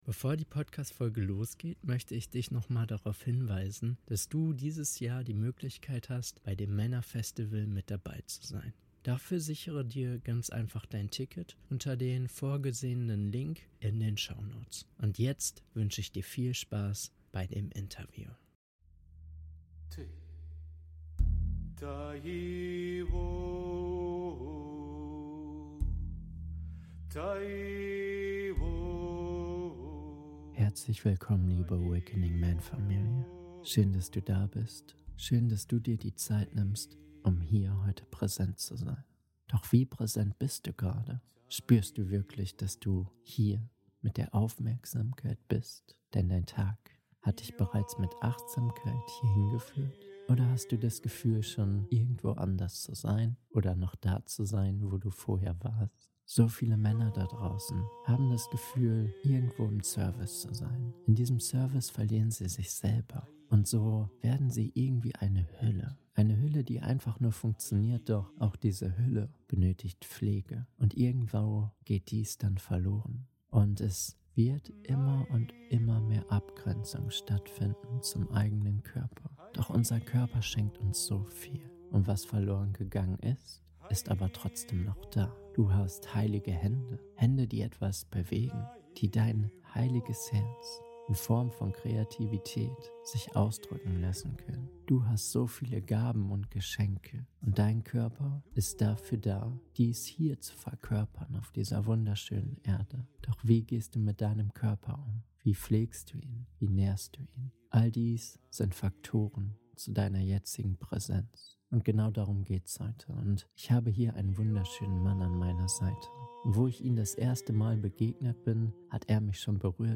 Energie im Essen - Interview